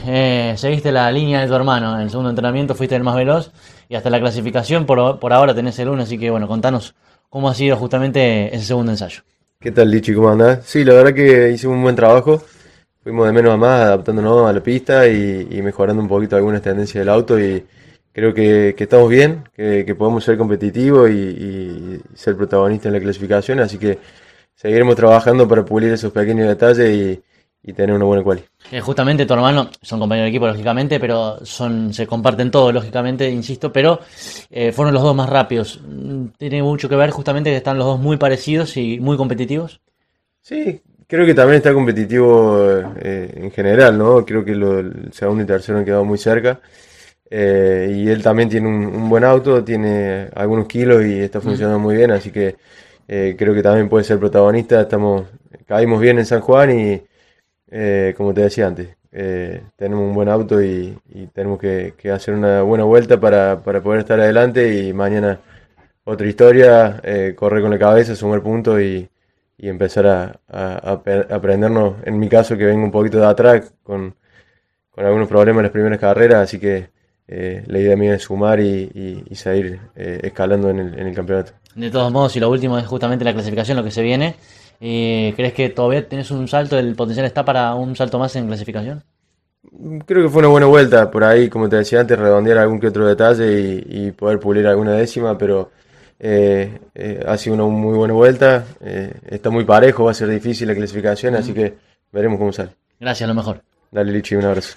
en diálogo exclusivo con CÓRDOBA COMPETICIÓN: